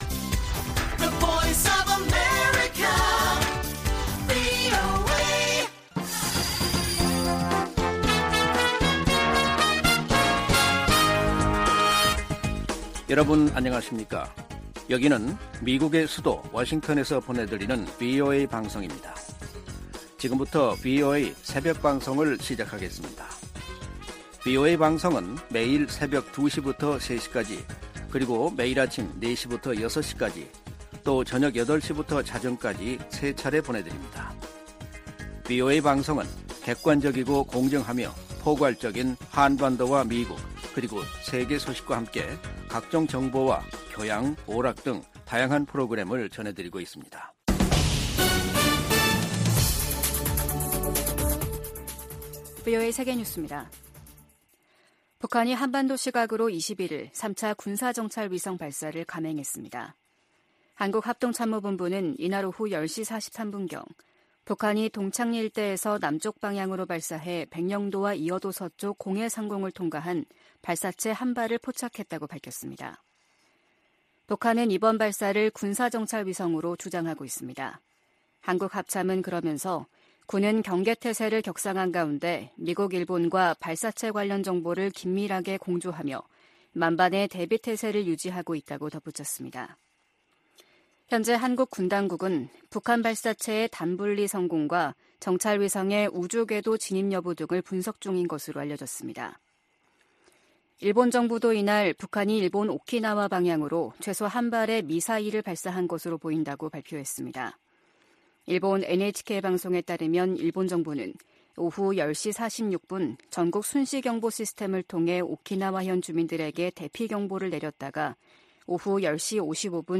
VOA 한국어 '출발 뉴스 쇼', 2023년 11월 22일 방송입니다. 국제해사기구(IMO)는 북한이 오는 22일부터 내달 1일 사이 인공위성 발사 계획을 통보했다고 확인했습니다. 북한의 군사정찰위성 발사 계획에 대해 미 국무부는 러시아의 기술이 이전될 가능성을 지적했습니다. 한국 정부가 남북 군사합의 효력 정지를 시사하고 있는 가운데 미국 전문가들은 합의 폐기보다는 중단했던 훈련과 정찰 활동을 재개하는 편이 낫다고 진단했습니다.